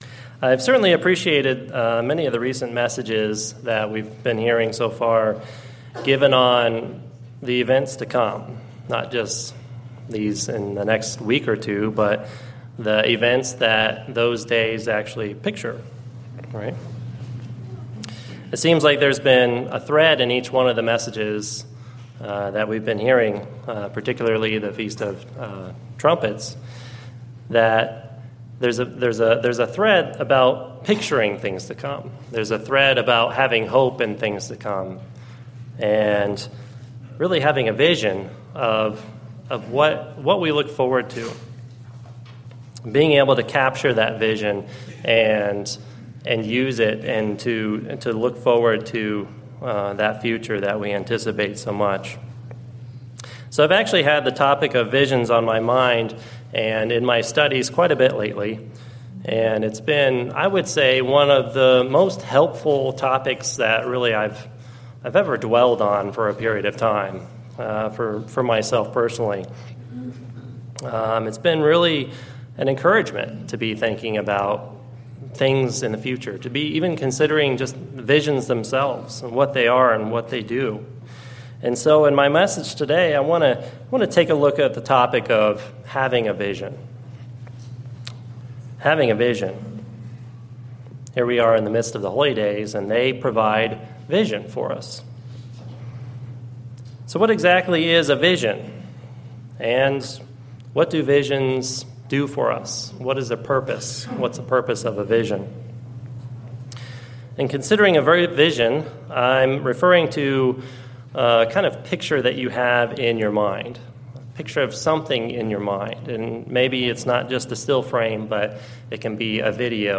Webcast Sermons